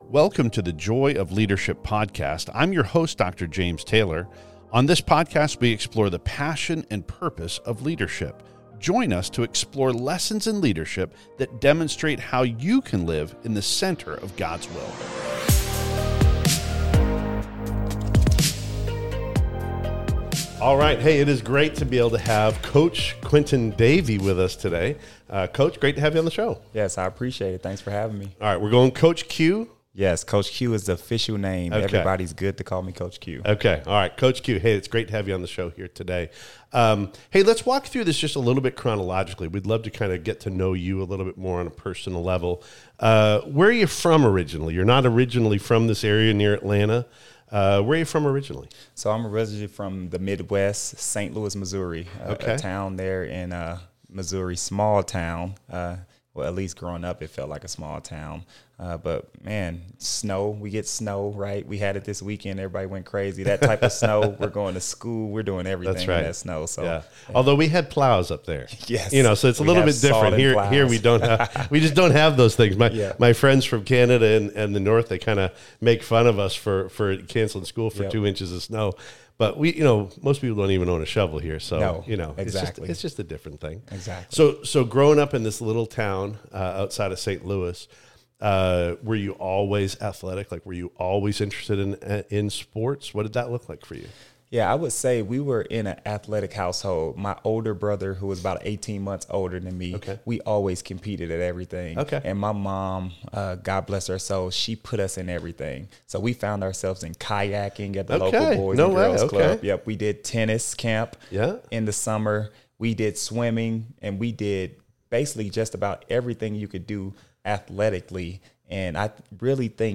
On this podcast, we explore the passion and purpose of leadership. We do so by talking with recognized leaders who do not merely have jobs, but men and women who are called to their chosen sphere of influence.